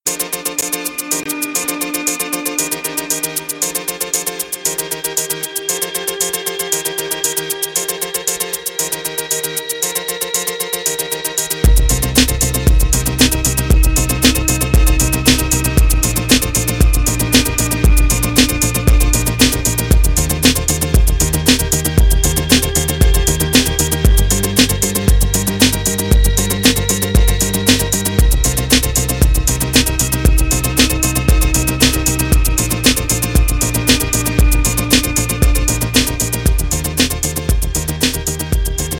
contemporary IDM/electronix and the sound of ‘80s synth pop